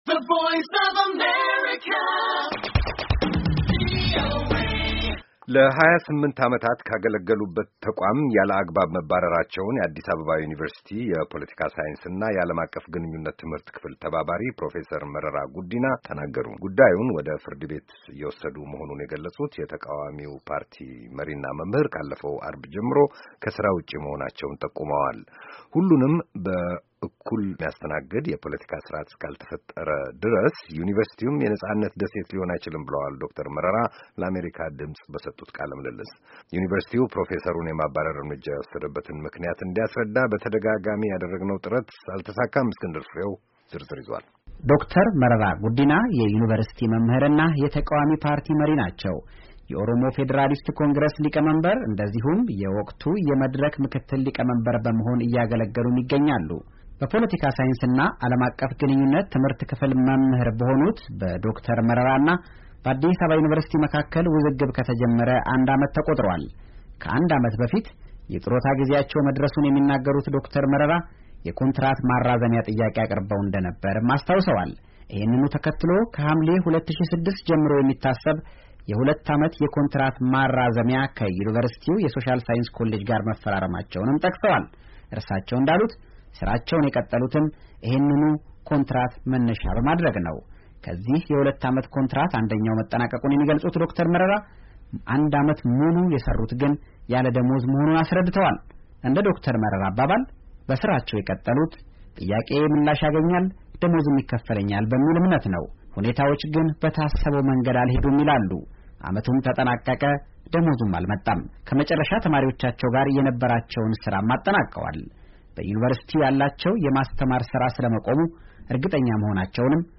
“ሁሉንም በእኩልነት እንደሚያድተናግድ የፖለቲካ ሥርዓት እስካልተፈጠረ ድረስ ዩኒቨርሲቲውም የነፃነት ደሴት ሊሆን አይችልም” ብለዋል ዶክተር መረራ ለአሜሪካ ድምፅ በሰጡት ቃለ-ምልልስ።